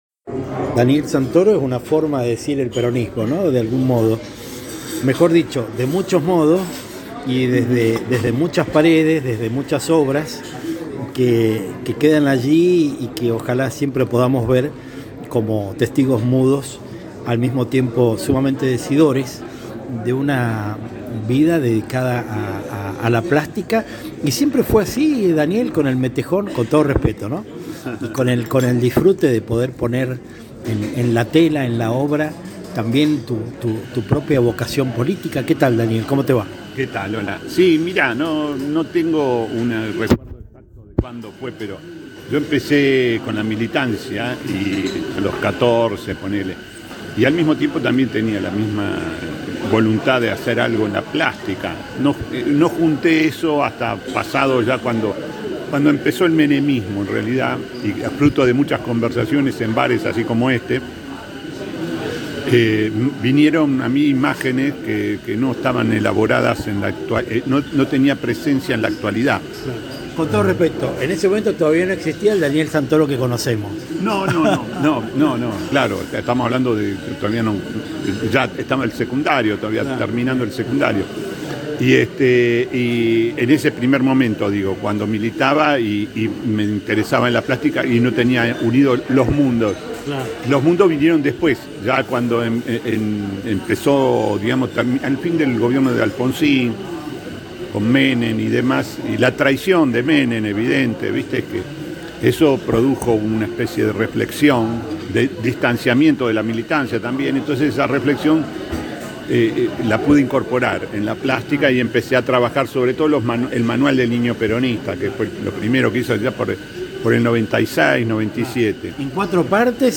La cosa es que con un café bien caliente y en el hermoso contexto del bar La London, como podrás corroborar a tris de un clic, líneas abajo.